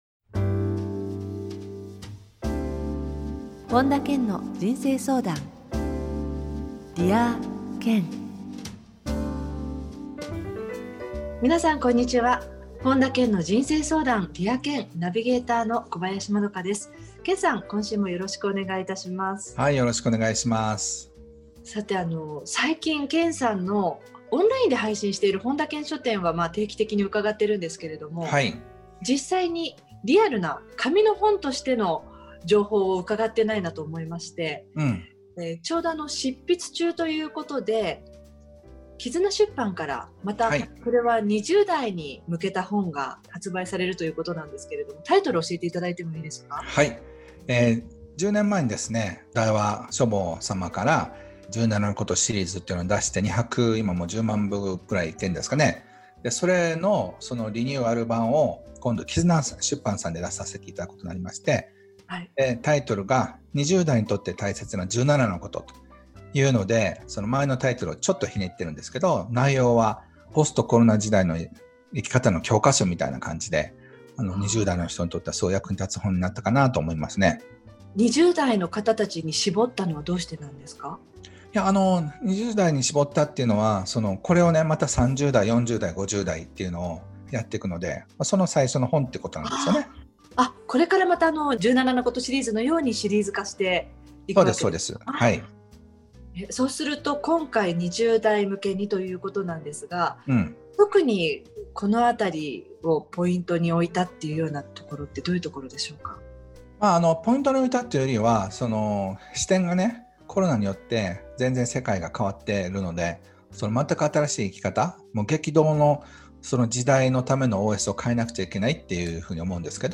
今回は「オンラインで人間関係を深める方法」をテーマに、本田健のラジオミニセミナーをお届けします。